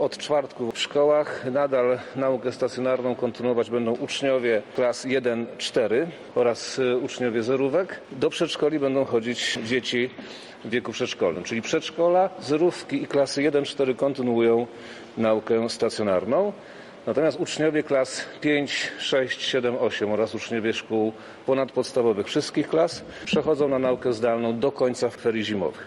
Jak przekazał na konferencji prasowej minister edukacji i nauki Przemysław Czarnek, nauka stacjonarna zostanie ograniczona.